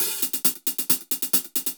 Index of /musicradar/ultimate-hihat-samples/135bpm
UHH_AcoustiHatA_135-02.wav